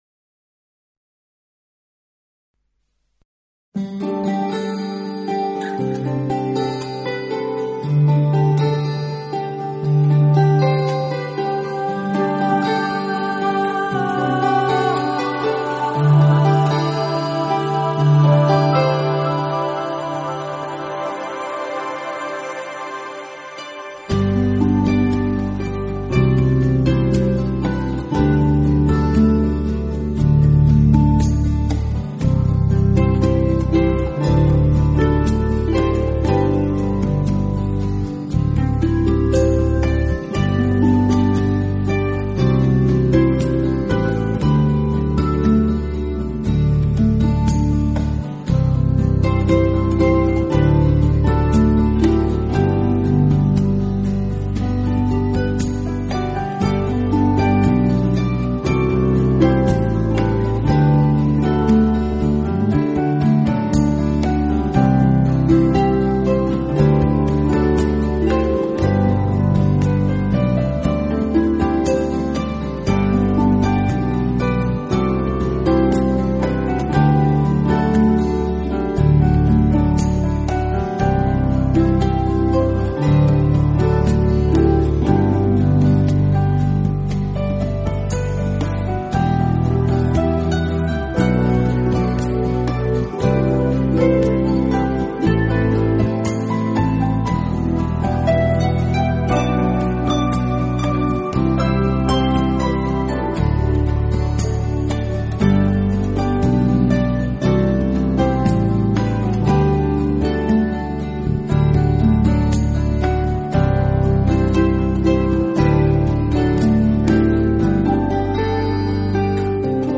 【纯音竖琴】
同时其录音又极其细致、干净、层次分明，
配器简洁明了，是近年唱片市场上难得的一套西洋音乐唱片。